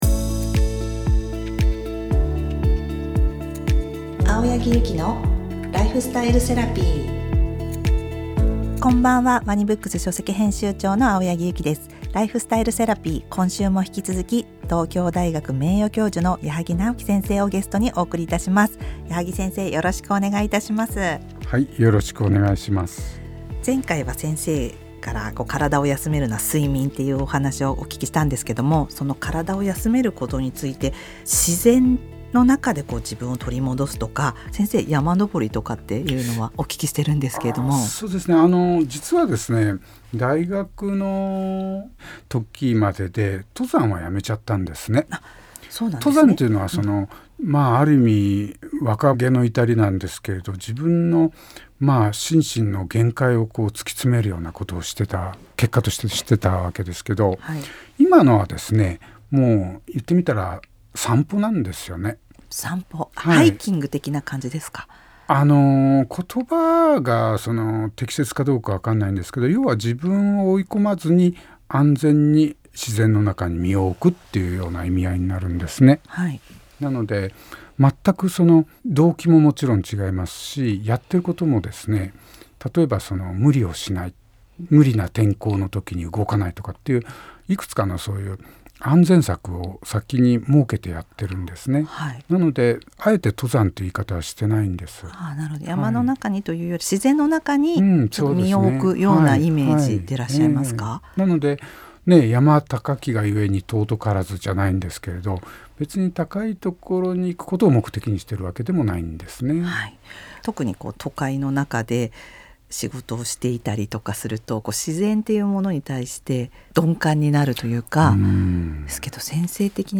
ライフスタイルセラピー「the ANSWER」今回のゲストは、東京大学名誉教授の矢作直樹先生をお招きしました。